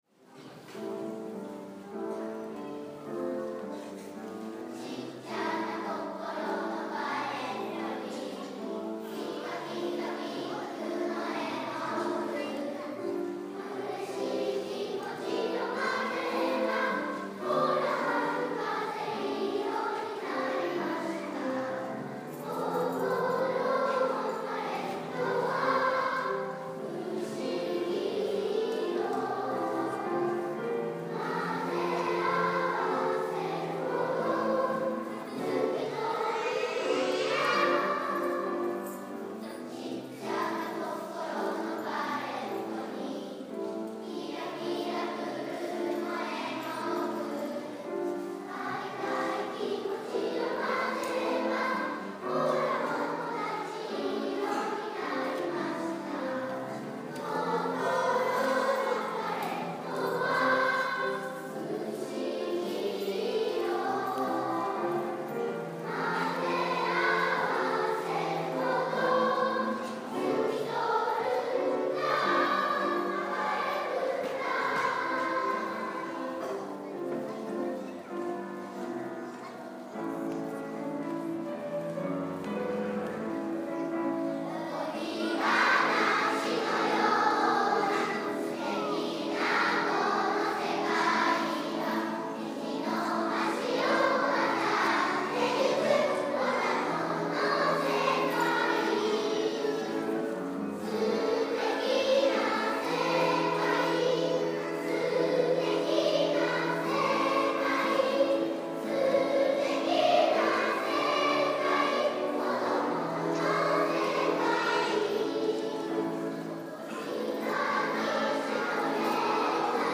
初めてコンサートをつくる１年生、そして、低学年チームのリーダーになった２年生が、音楽隊の音楽に合わせて入場します！
「こころパレット」の前奏が始まると、ピアノのリズムに合わせて子どもたちの体が自然と動き出します。
1年生と2年生が違うメロディーを重ね合わせて、一つのハーモニーをつくることにチャレンジしました。お互いのメロディーを大切にしながら、会場を低学年チームの歌声で包みます♪
たくさんの拍手に、子どもたちはとてもうれしそうな顔をしていました。
低学年チームの歌声は、とってもかわいい天使の歌声でした♪